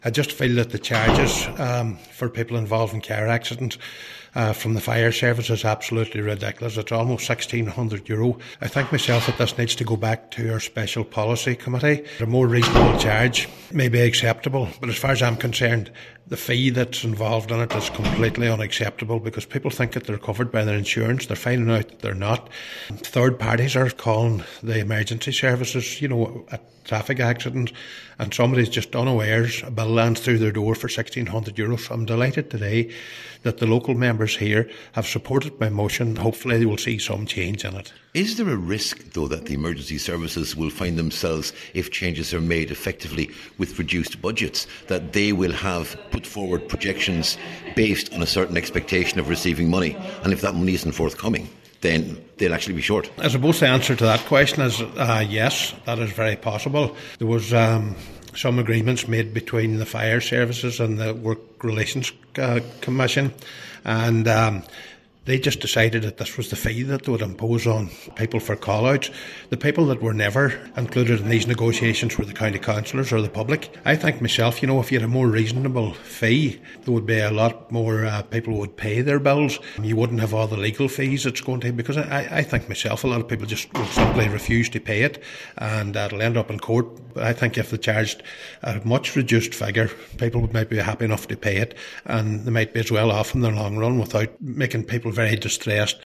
Cllr Michael McBride told a Letterkenny Milford Municipal District meeting that the cost of a call out is now €1,600, and while insurance companies will pay if the claimant calls the fire service themselves, they won’t if the call is made by a third party.